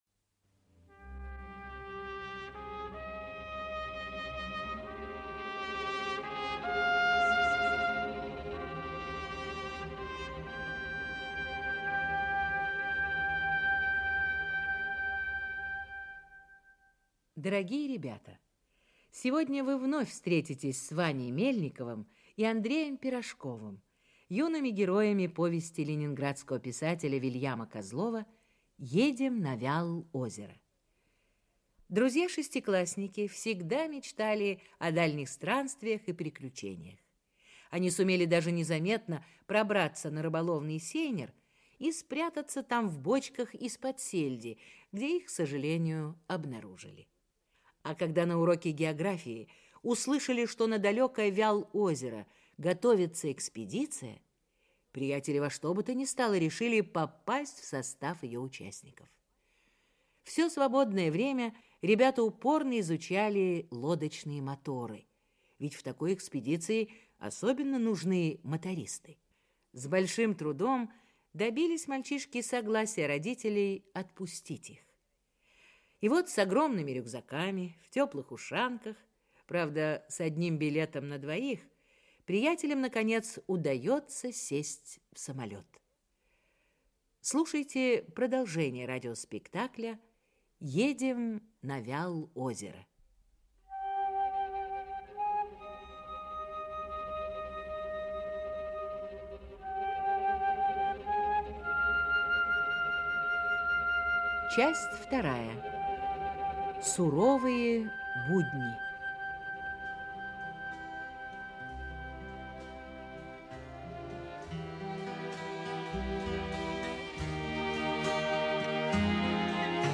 Аудиокнига Едем на Вял-озеро. Часть 2 | Библиотека аудиокниг
Прослушать и бесплатно скачать фрагмент аудиокниги